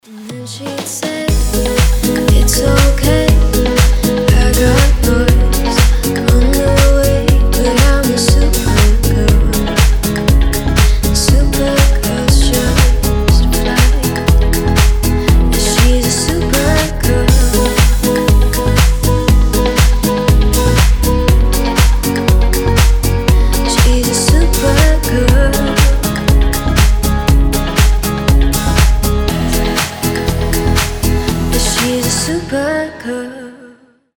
• Качество: 320, Stereo
deep house
retromix
женский голос
Cover
nu disco